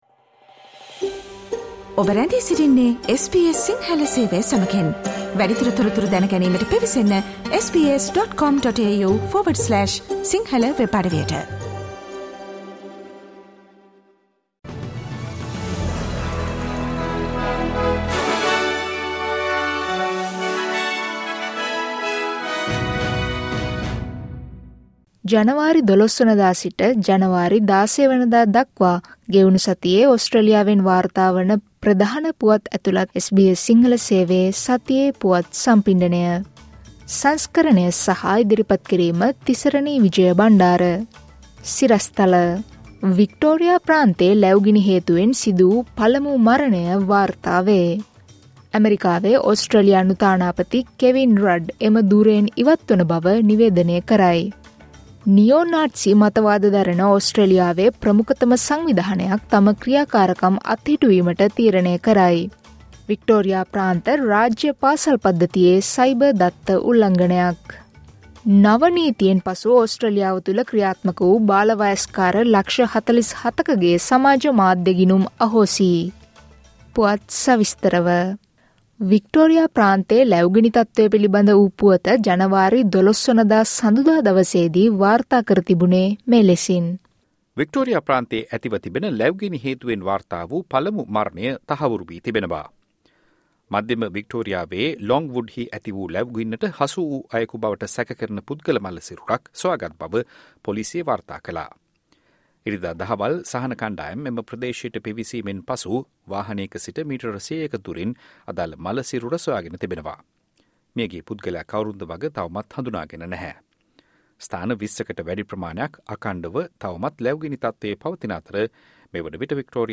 ජනවාරි 12වන දා සිට ජනවාරි 16වන දා දක්වා වන මේ සතියේ ඕස්ට්‍රේලියාවෙන් වාර්තා වන පුවත් ඇතුළත් SBS සිංහල සේවයේ සතියේ පුවත් ප්‍රකාශයට සවන් දෙන්න.